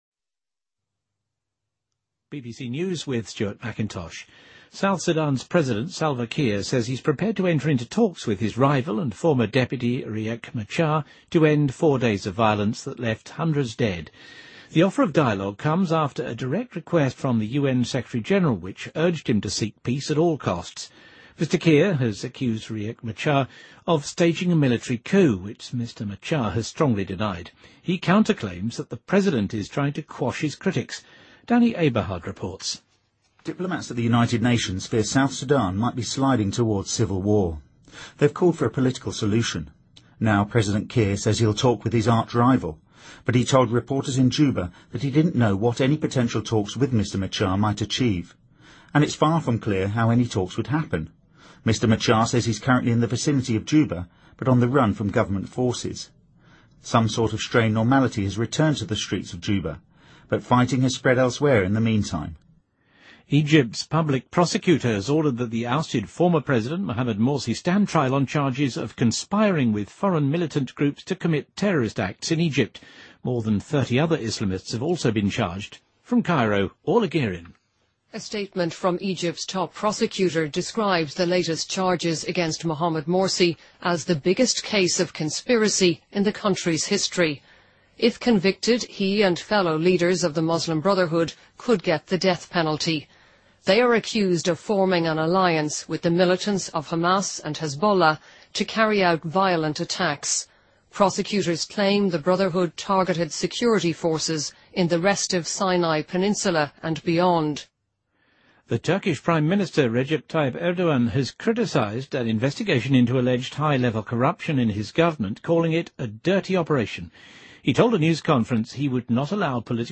BBC news,土耳其总理雷杰普·塔伊普·埃尔多安政府目前因高层腐败被调查